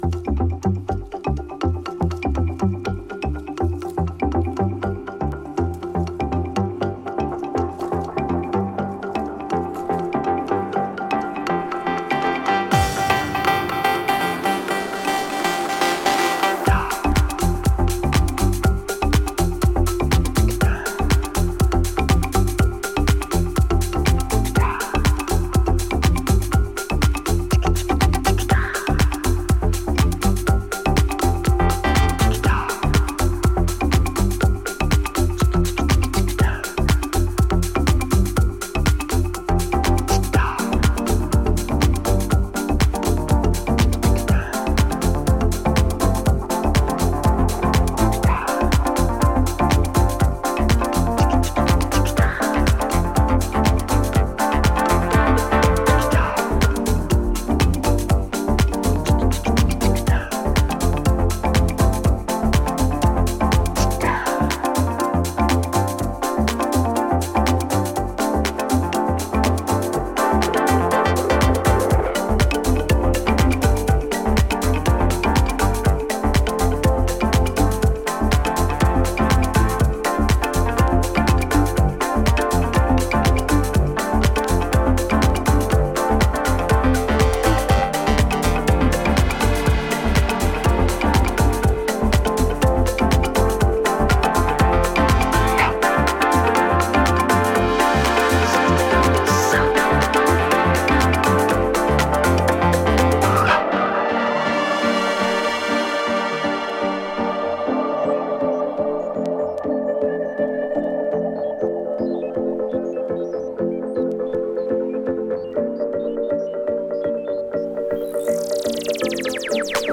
オーガニックなマリンバ使いが印象的な